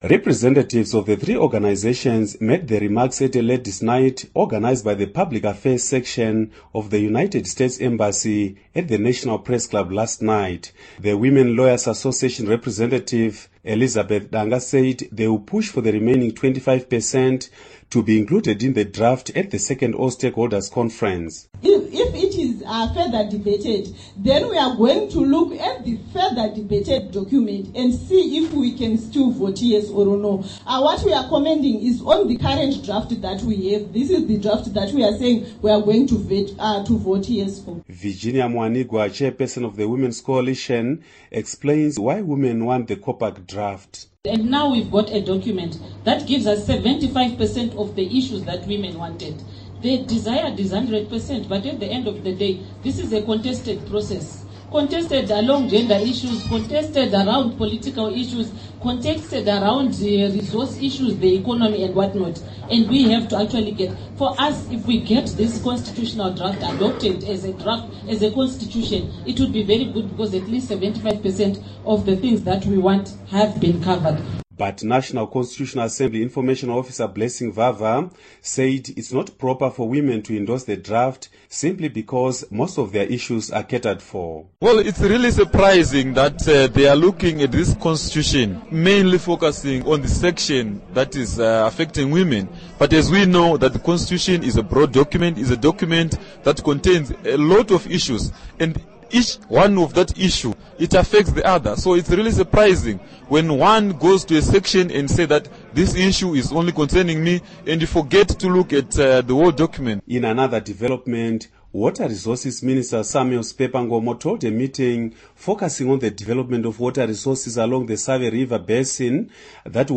Interview with Lindiwe Zulu